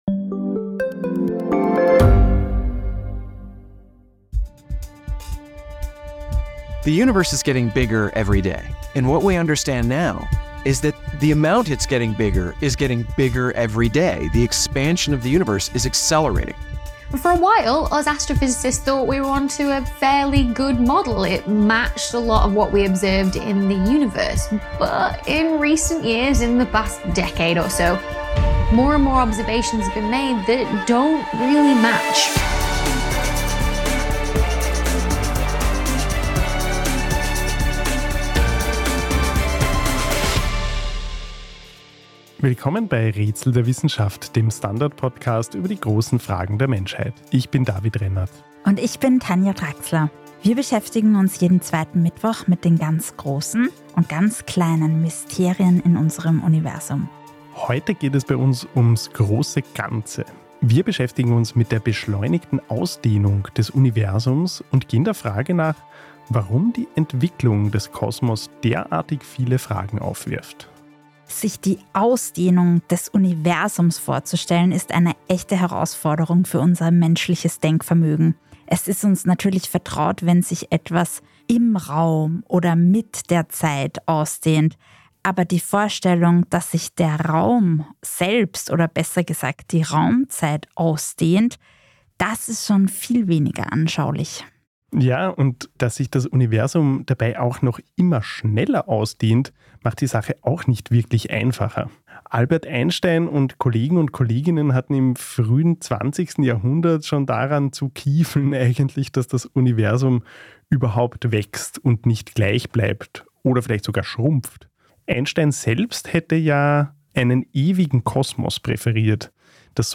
mit dem Physiknobelpreisträger Saul Perlmutter, der daran arbeitet, das größte Rätsel der Astronomie zu lüften.